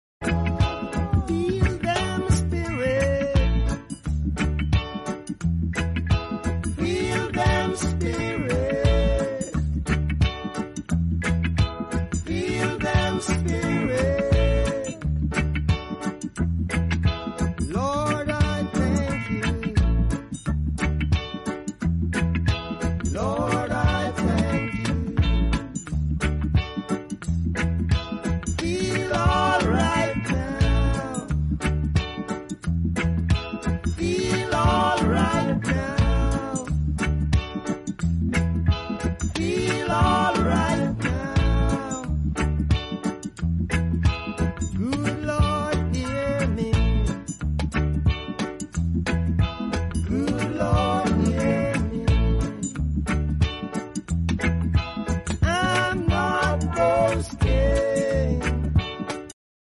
soulful track
reggae groove
spiritual anthem